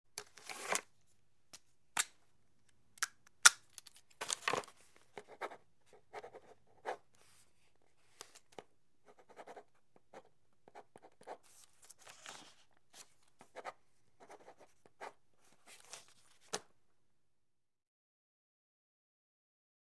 Pen Cap Off, Writing; Movement On Desk, Pen Cap Off Writing On Pad. - Pen Writing